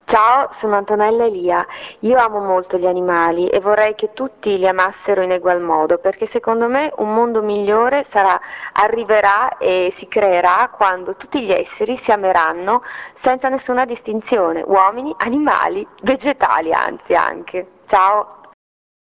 ASCOLTA GLI SPOT DI ANTONELLA ELIA